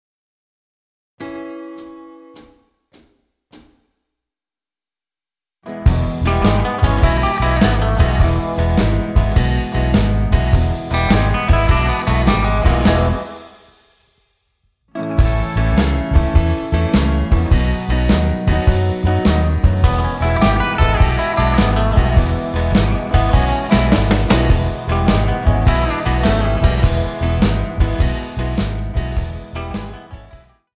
--> MP3 Demo abspielen...
Tonart:E ohne Chor